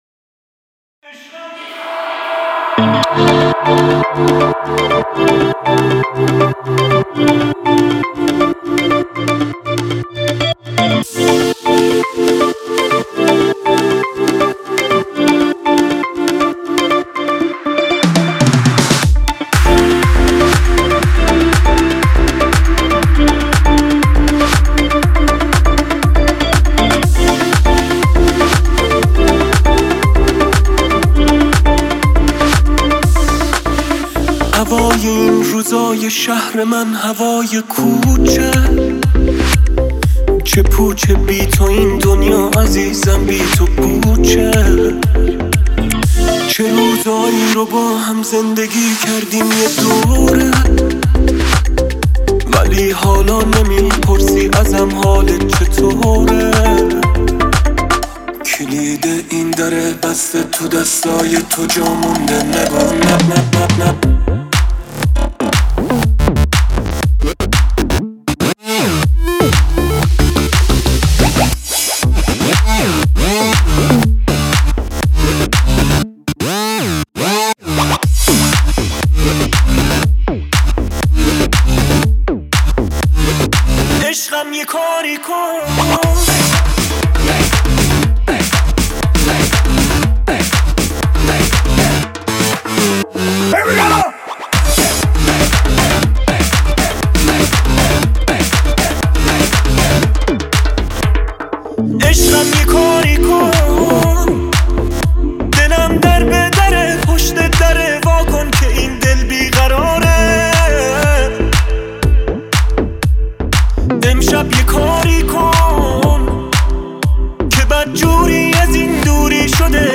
Progressive Electronic Mix